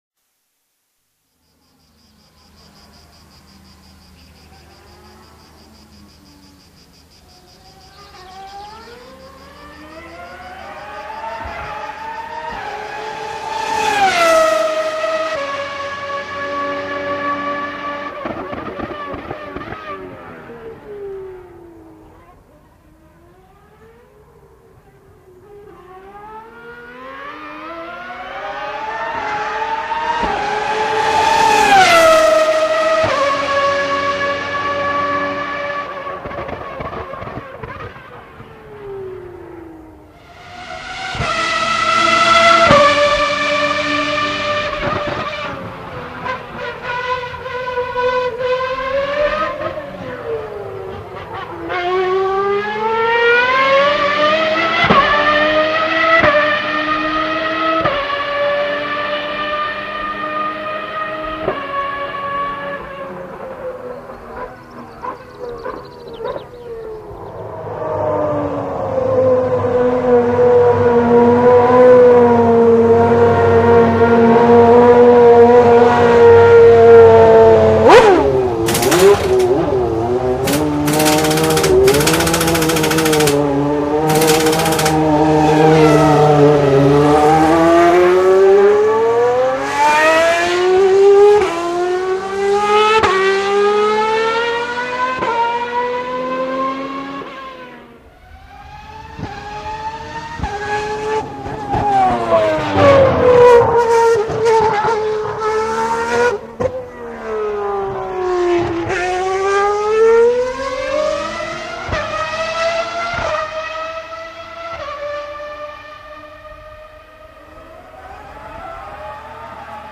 Sinon, si t'aimes la F1, j'ai ce ptit son qui me fait dresser le z*z* :twisted: pendant 4 minutes.
Si t'as de bon bafles, fait toi plaisir, c'est de toute bonne qualité :D